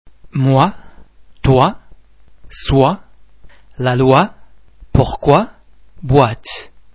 oi oê oe oy [Wa][ typically French sound]
The French [wah ] sound is very much like the [w] sound in English words why, wide, wet.
wa_moi.mp3